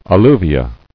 [al·lu·vi·a]